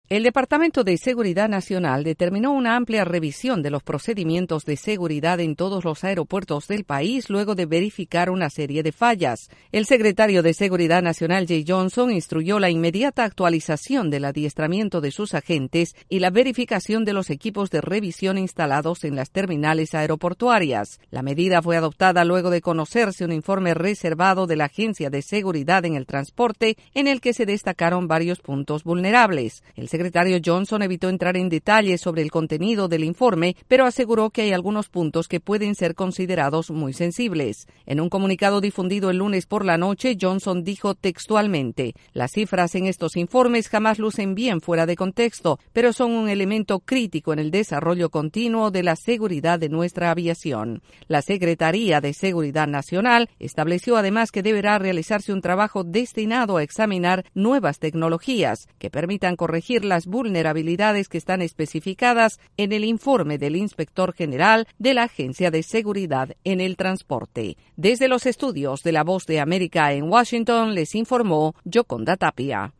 Los sistemas de seguridad en los aeropuertos y un nuevo adiestramiento de sus agentes como medida para mejorar los servicios en las terminales de todo Estados Unidos. Desde los estudios de la Voz de América